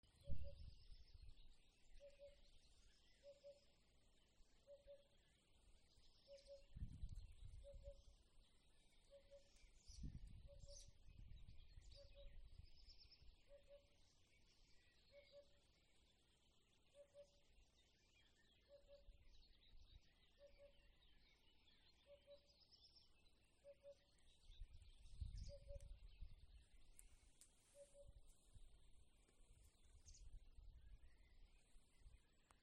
Hoopoe, Upupa epops
Notes /pūpina pie mājas staba augšā, pamanīts pārlido tuvējās liepās un atkal pūpina, vai var likt ligzdošanu biotopā?